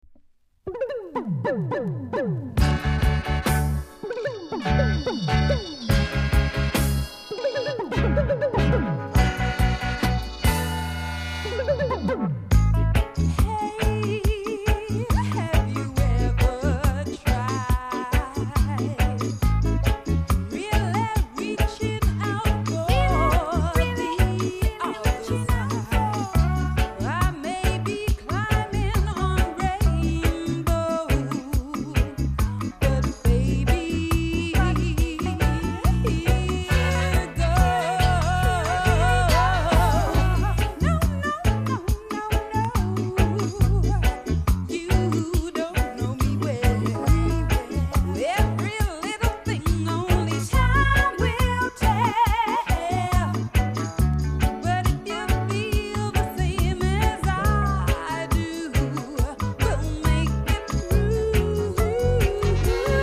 LOVERS ROCK
※小さなチリノイズが少しあります。
BIG COVER!!